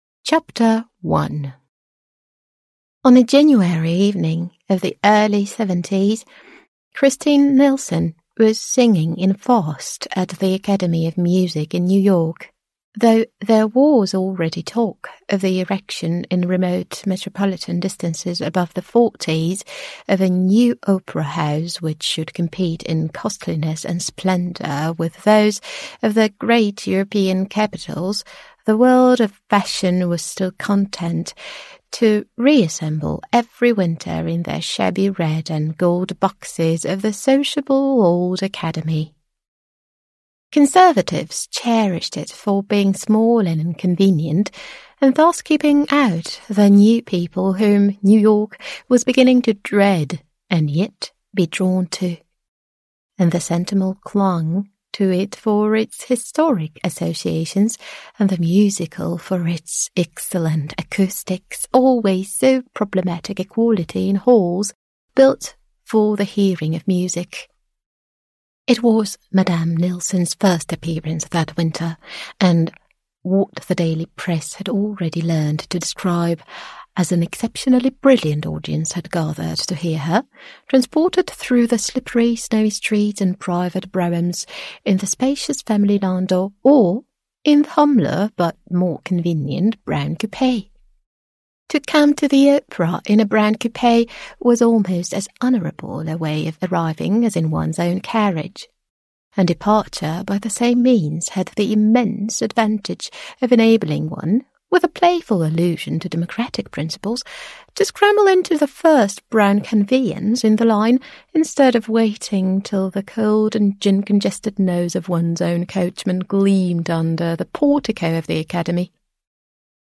AUDIOBOOK (mp3) Wiek niewinności w wersji do nauki angielskiego